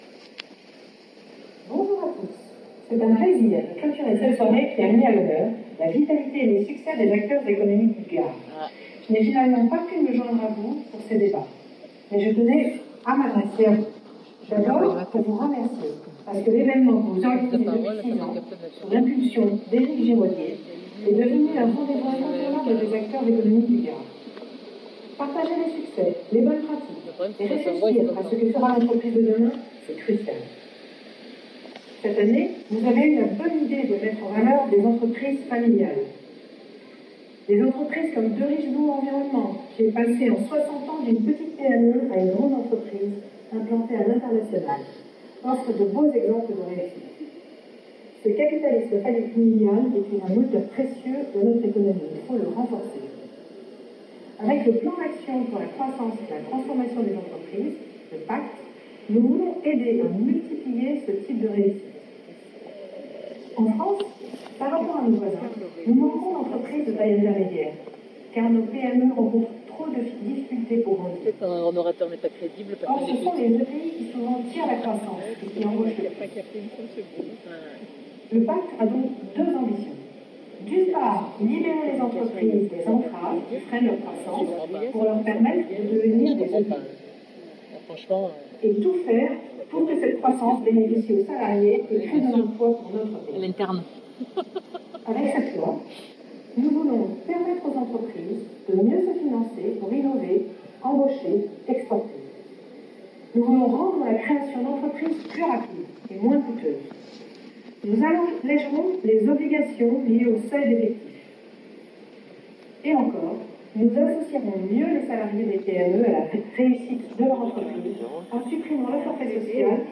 Hier soir, j’ai assisté à une soirée d’UPE 30 (la 1ere organisation économique de la région et réseau d’entrepreneurs dynamique).
Ce ton lancinant et ronronnant ou aussi, lorsque l’on lit un texte sans aucune conviction… Sans aucune incarnation…  Ben voilà, c’est ça parler faux !
Très rapidement, le public s’est mis à parler, à faire des apartés, à chahuter car en se filmant de la sorte, cette dame s’est décrédibiliser et on ne faisait plus attention qu’à la forme de sa prise de parole…(comme vous l’entendrez sur l’enregistrement audio) Et petit à petit dans l’auditoire, plus personne n’écoutait le fond de son allocution.
Ici, il y a eu au moins 4 accrochages bien marqués, qui ont été enregistrés et immortalisés sur la vidéo.
Intervention-Secretaire-etat.mp3